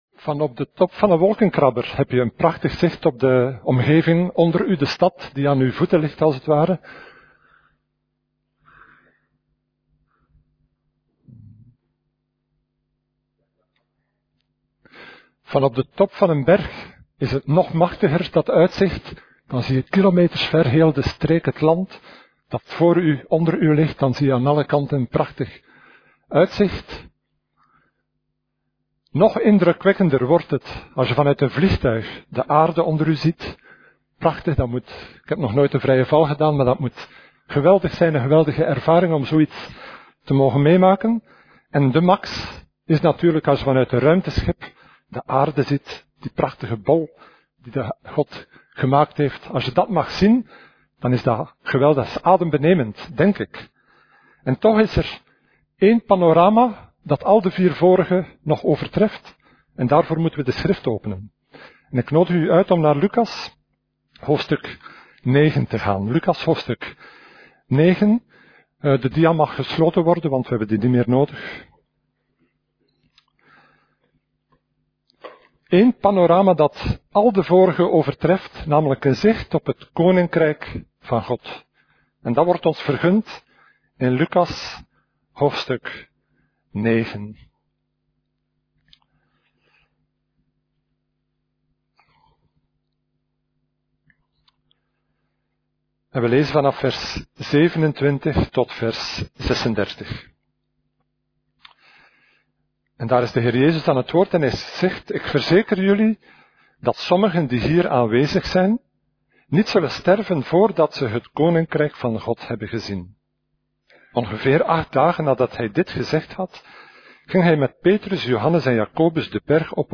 Preek: Een voorproef van Gods Koninkrijk - Levende Hoop